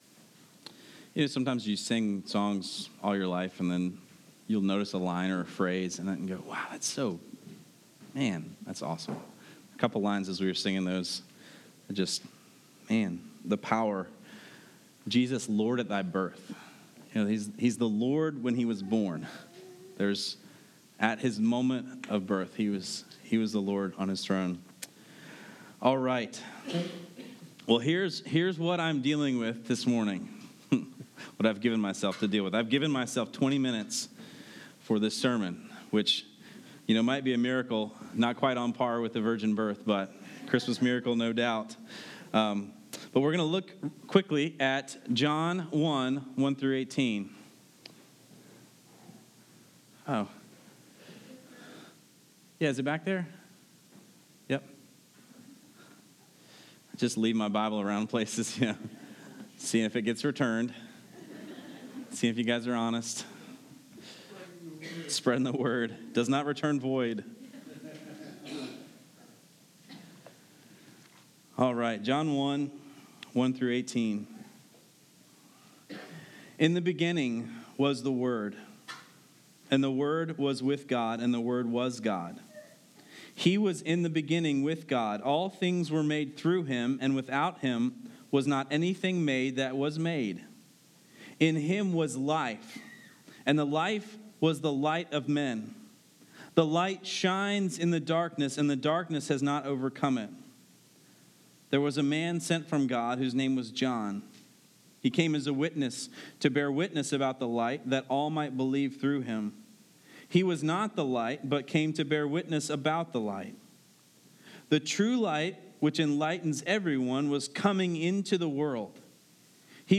I gave myself 20 minutes for this sermon.